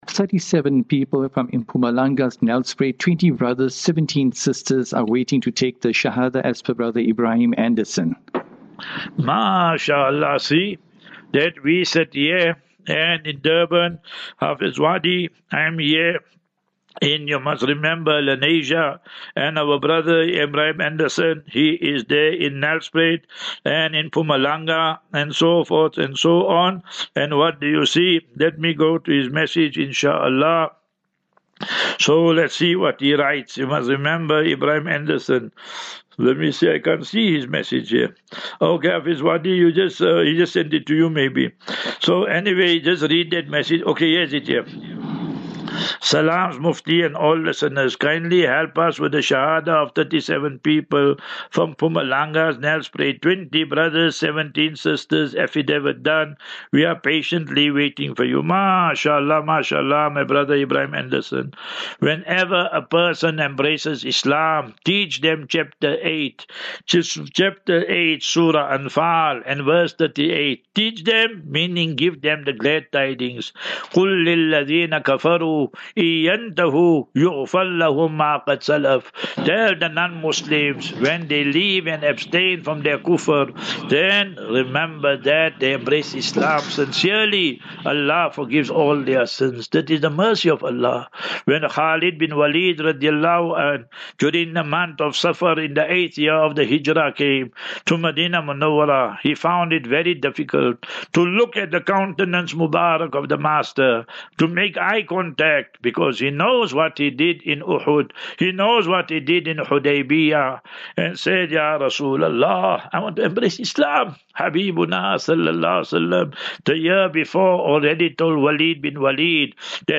Lectures 15 Dec 15 Dec 24